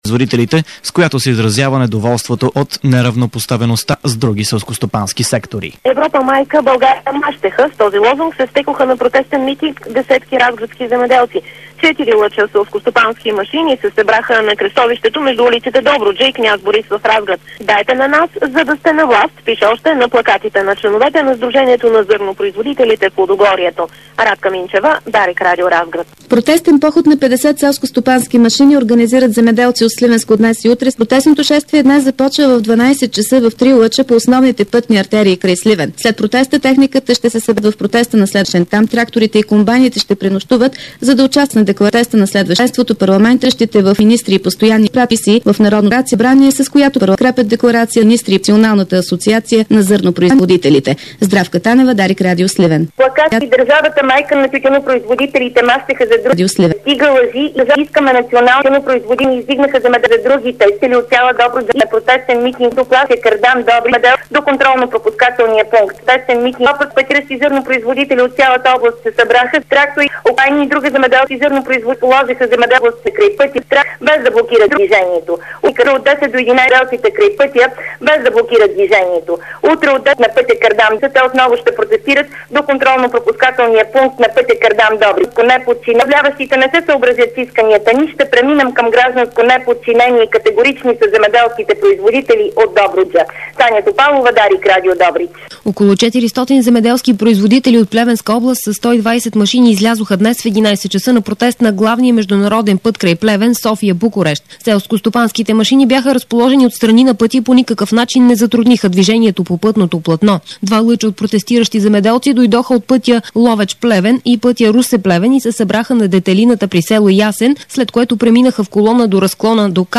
Обедна информационна емисия - 29.11.2007